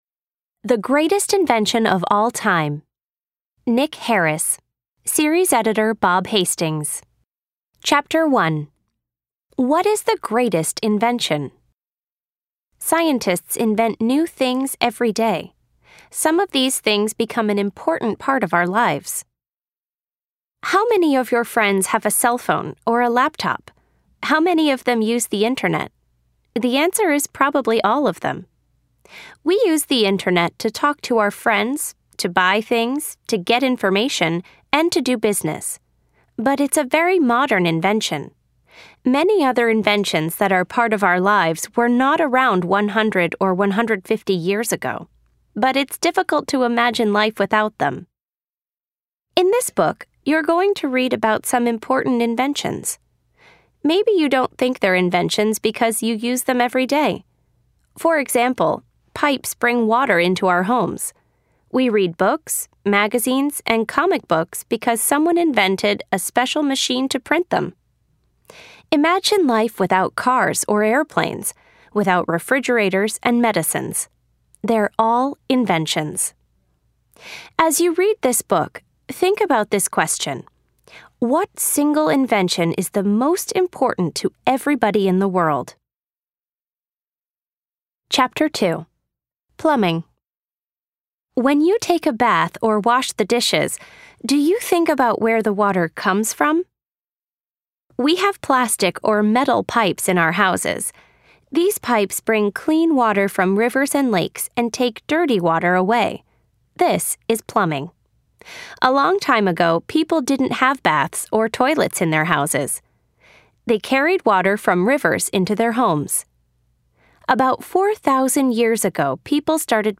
Type : Short Story